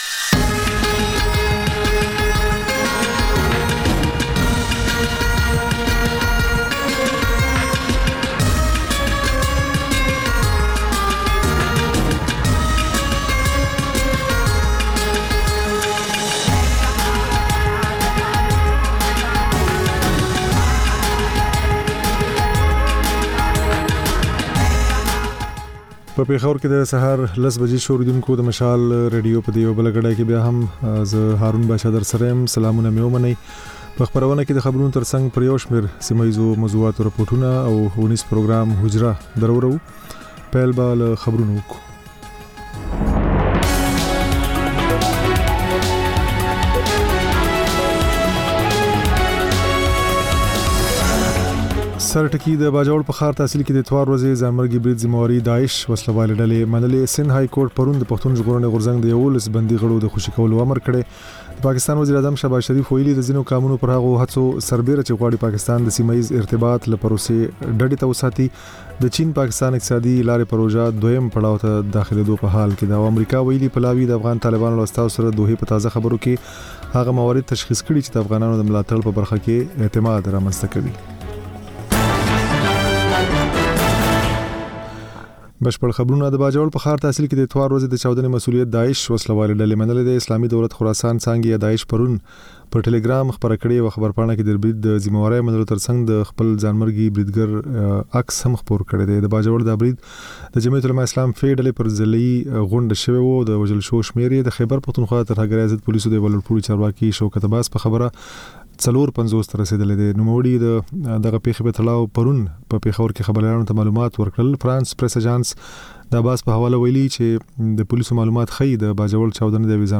په دې خپرونه کې تر خبرونو وروسته بېلا بېل رپورټونه، شننې او تبصرې اورېدای شﺉ.